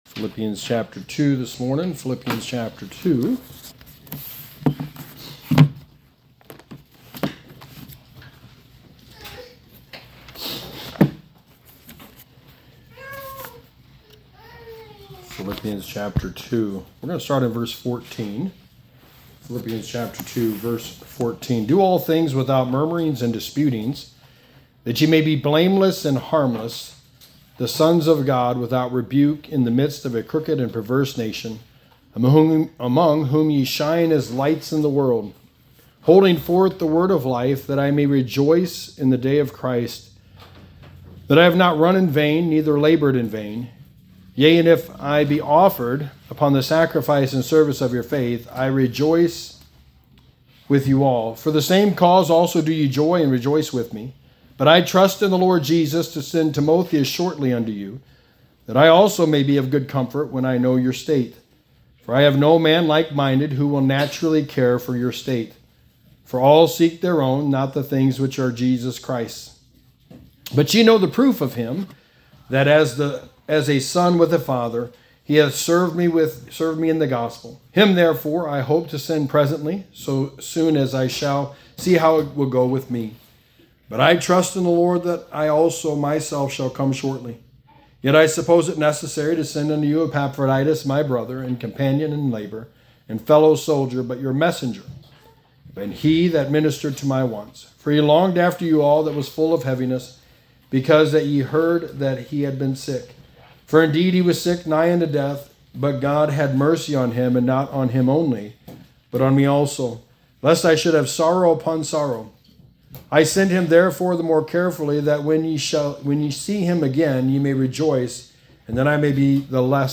Passage: Philippians 2:14-30 Service Type: Sunday Morning